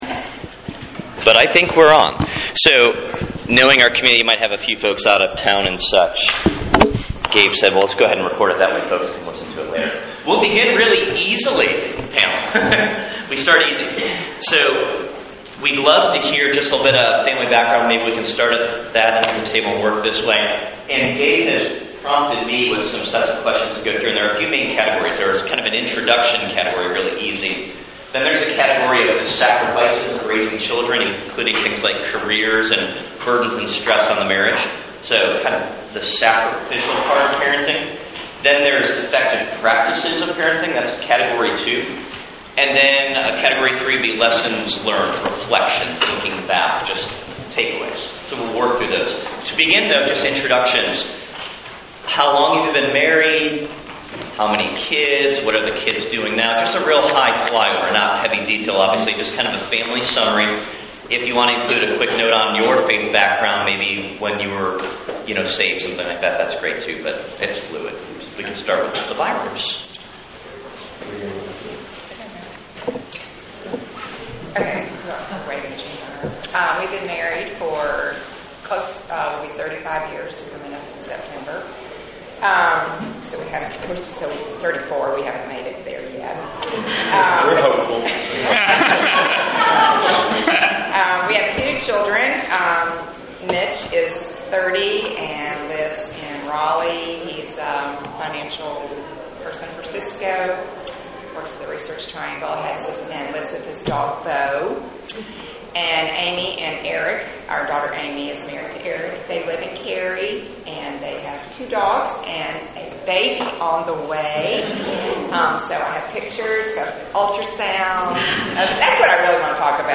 My friends, recently we hosted a parenting panel forum at our church. We invited our elders and their wives to share advice on several aspects of parenting.
parenting-panel-2016.mp3